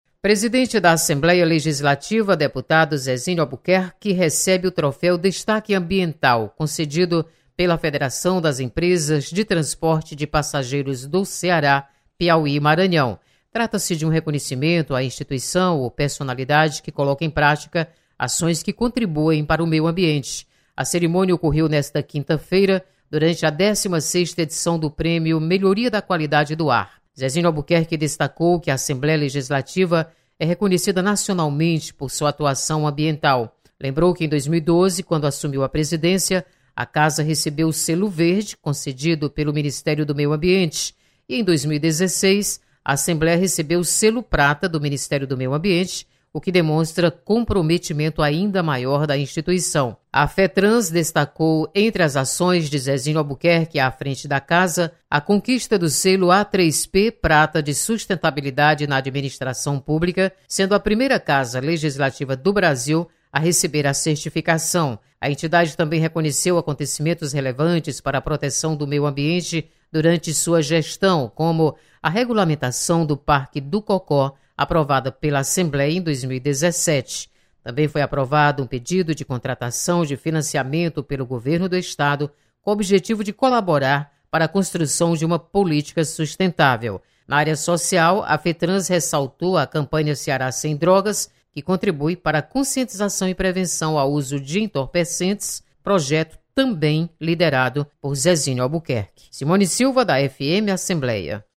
Presidente Zezinho Albuquerque recebe homenagem do CEPIMAR. Repórter